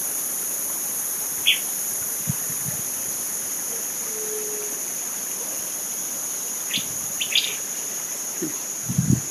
Fiofío Oliváceo (Elaenia mesoleuca)
Nombre en inglés: Olivaceous Elaenia
Localización detallada: Estancia Virocay
Condición: Silvestre
Certeza: Vocalización Grabada